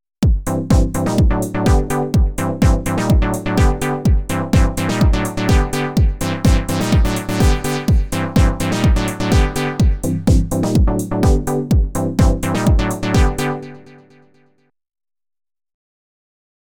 Sound Design : Techno stab
did you guys know that sy bits can do three note chords (on one track)? :wink: